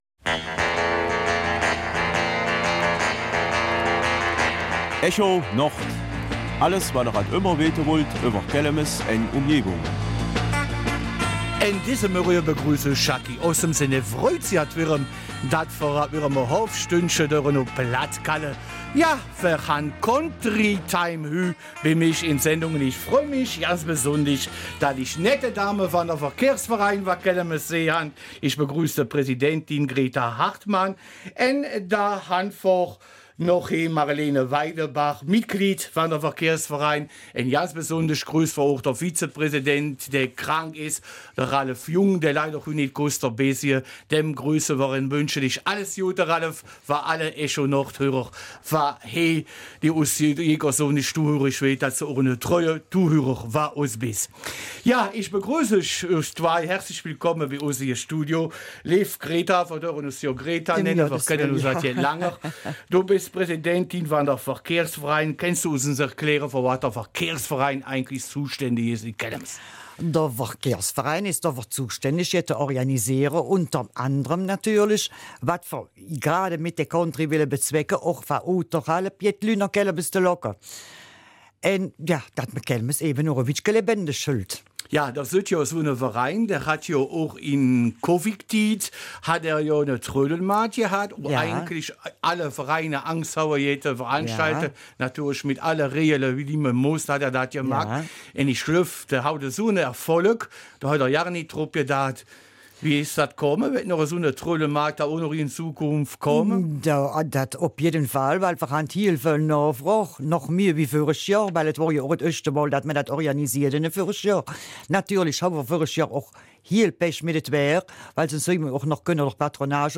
Kelmiser Mundart: Country-Nachmittag in Kelmis